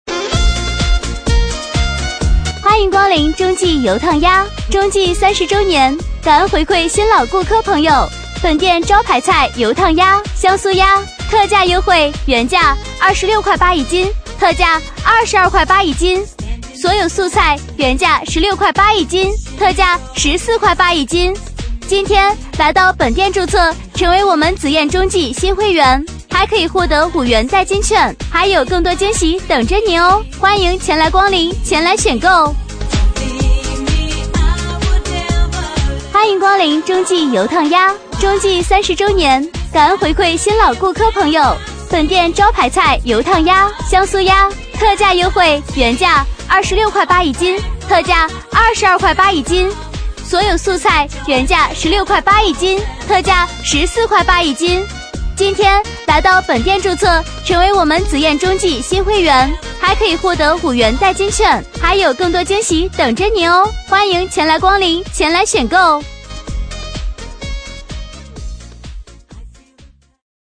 B类女13
【女13号促销】钟记油烫鸭